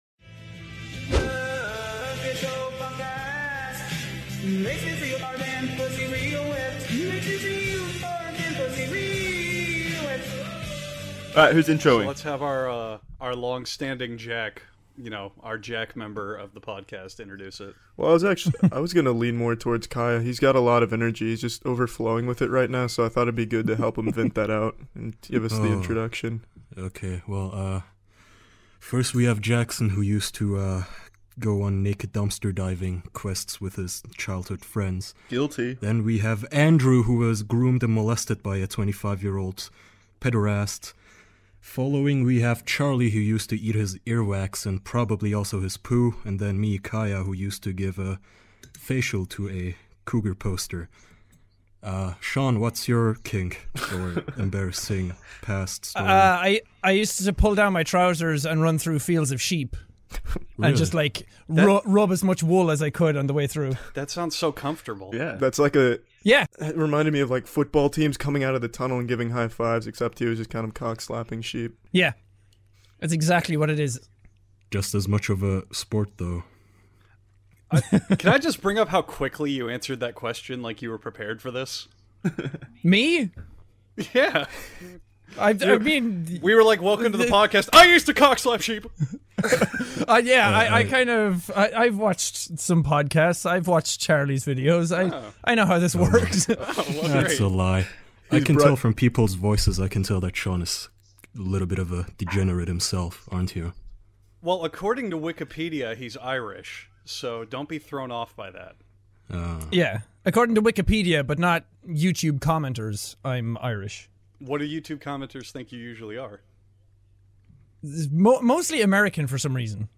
Five close man friends gather around to be YouTube famous.